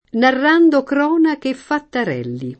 fatterello [fatter$llo] s. m. — anche fattarello [fattar$llo]: Narrando cronache E fattarelli [